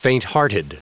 Prononciation du mot fainthearted en anglais (fichier audio)
Prononciation du mot : fainthearted